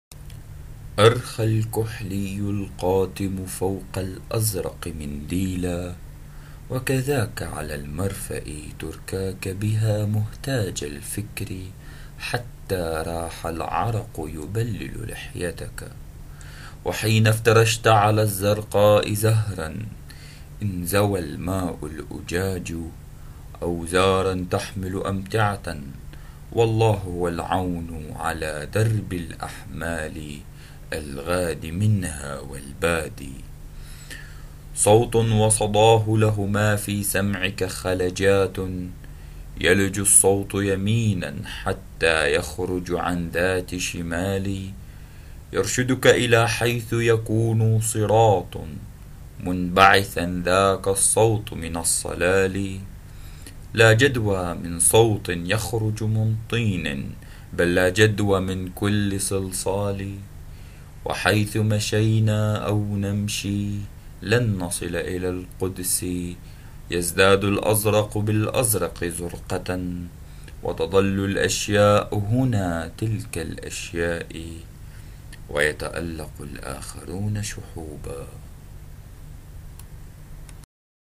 translation and voice: